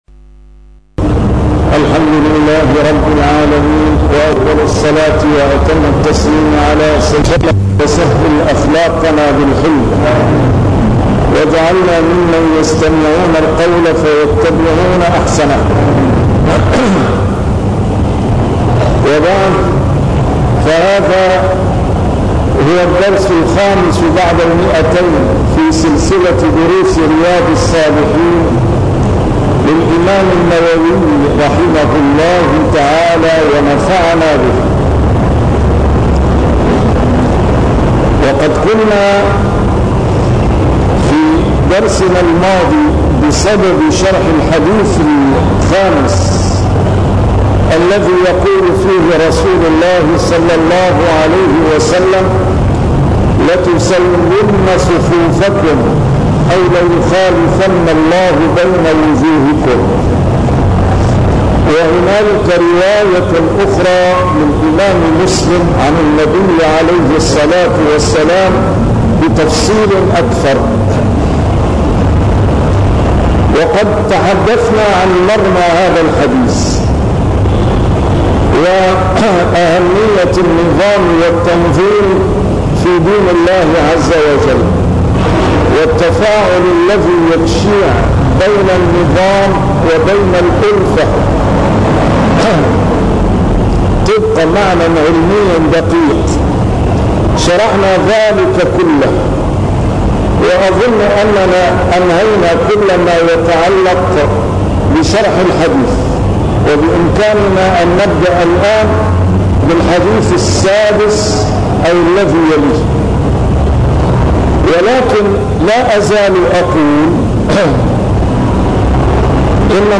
A MARTYR SCHOLAR: IMAM MUHAMMAD SAEED RAMADAN AL-BOUTI - الدروس العلمية - شرح كتاب رياض الصالحين - 205- شرح رياض الصالحين: المحافظة على السنة